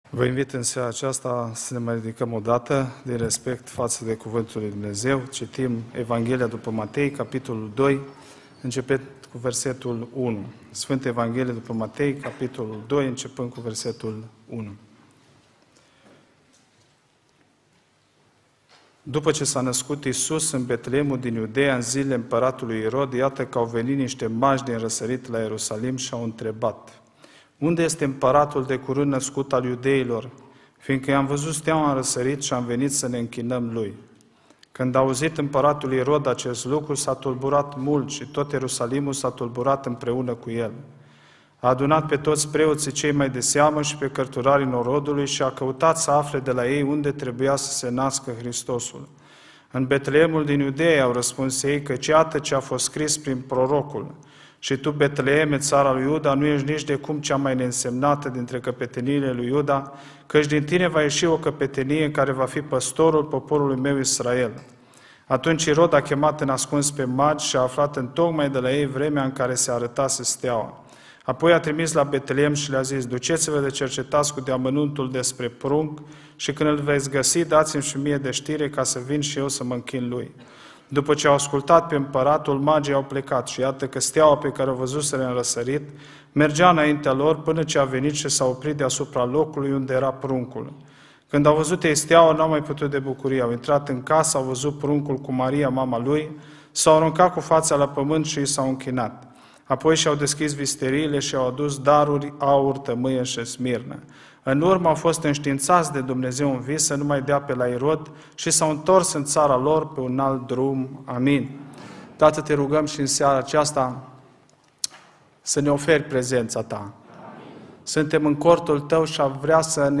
Acesta predica a fost impartita in 7 parti: 1, 2, 3, 4, 5, 6 si partea a 7-a